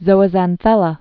(zōə-zăn-thĕlə)